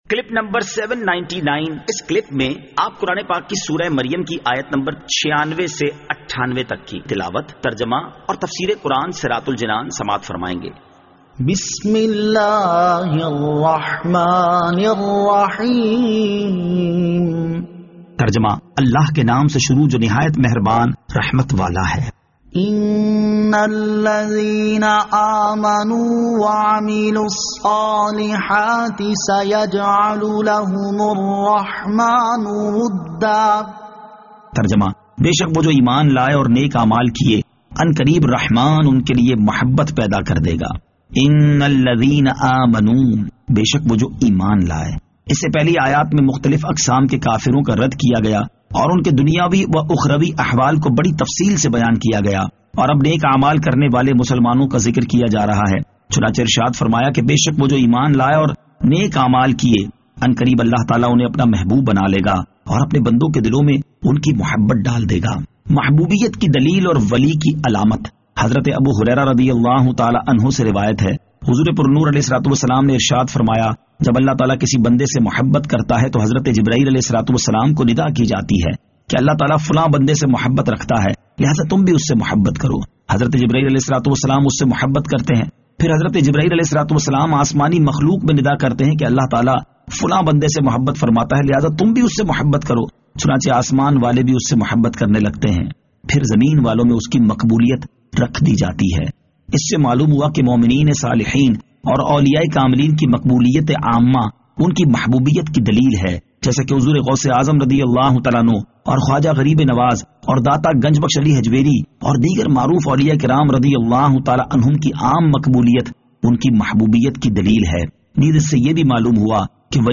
Surah Maryam Ayat 96 To 98 Tilawat , Tarjama , Tafseer